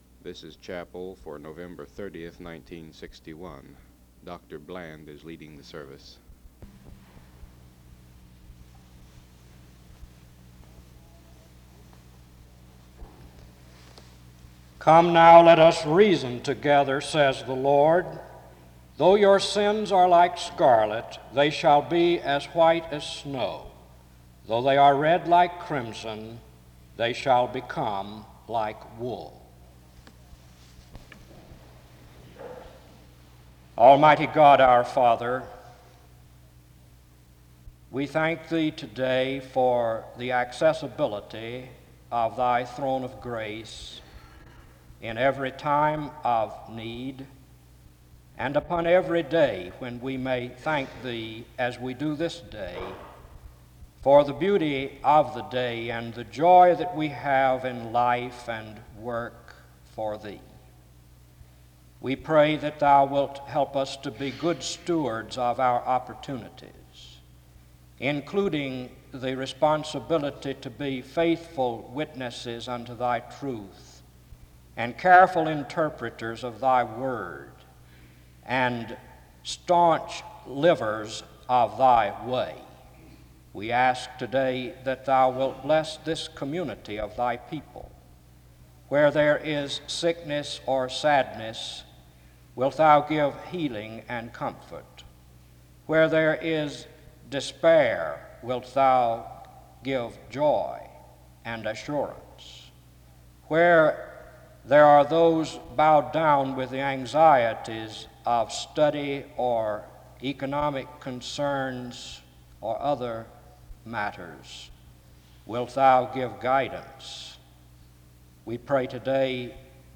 The service begins with the reading of Isaiah 1:18 (00:00-00:32) and prayer (00:33-02:44).
We must also learn the content of prayer from Jesus’s sample prayer (13:07-19:24). He ends his time in prayer (19:25-19:44), and the service ends with singing (19:45-21:04).